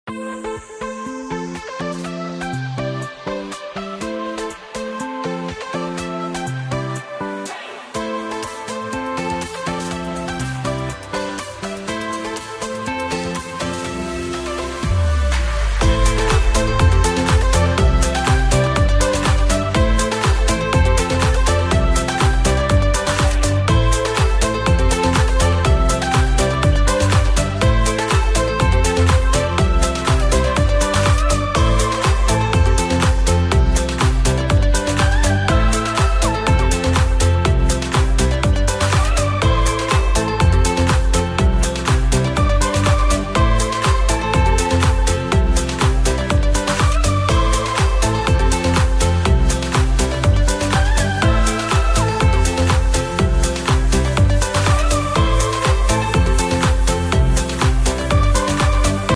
Summer Vibes